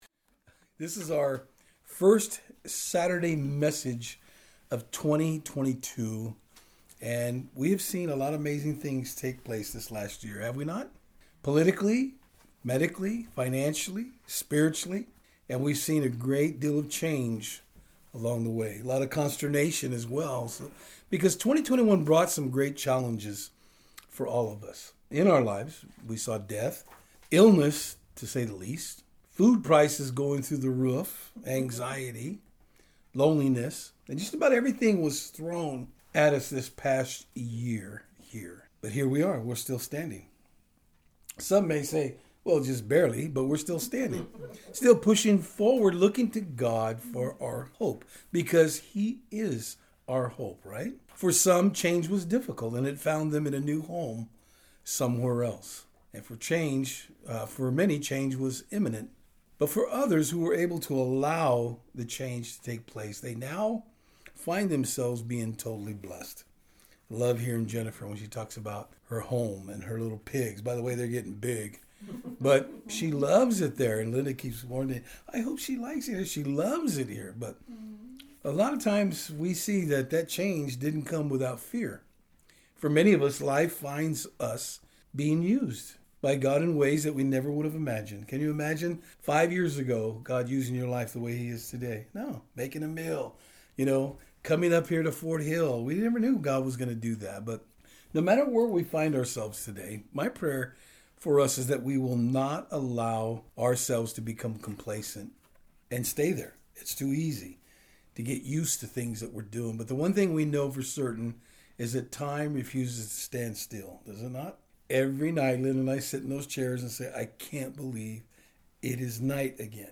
Proverbs 3:1-10 Service Type: Saturdays on Fort Hill Welcome 2022!